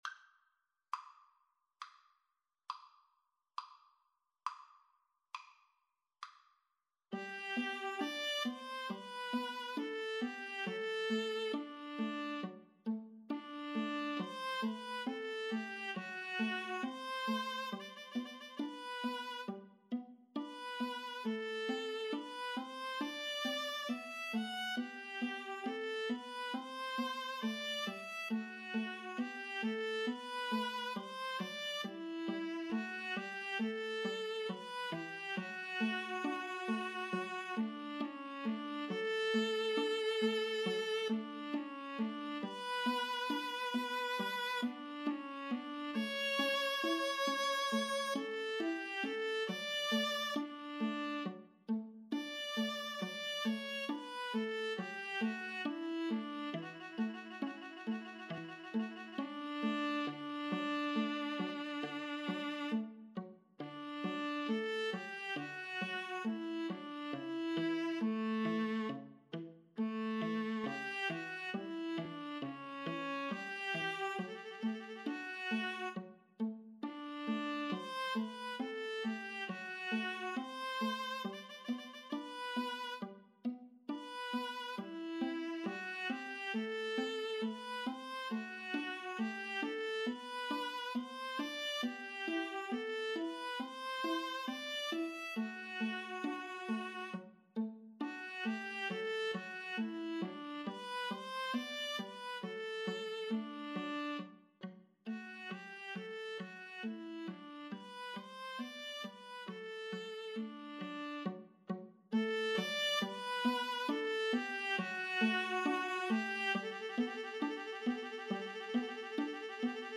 = 34 Grave
4/4 (View more 4/4 Music)
Classical (View more Classical Viola Trio Music)